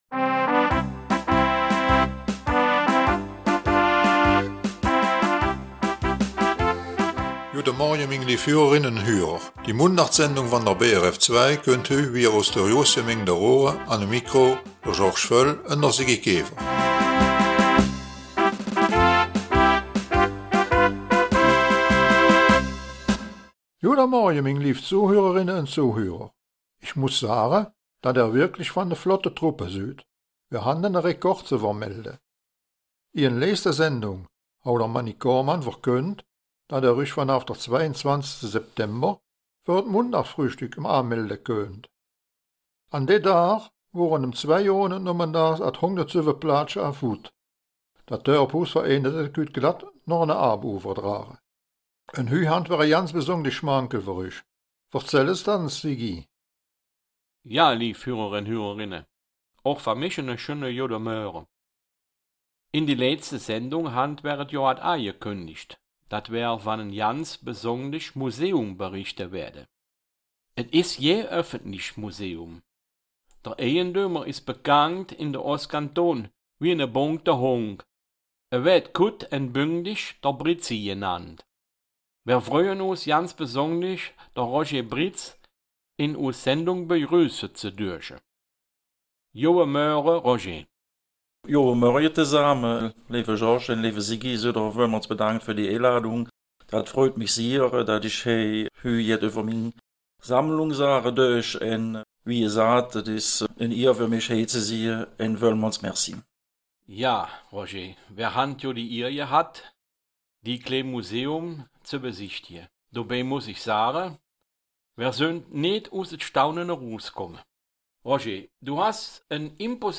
Raerener Mundart - 12. Oktober
Die Mundartsendung vom 12. Oktober aus Raeren bringt folgendes Thema: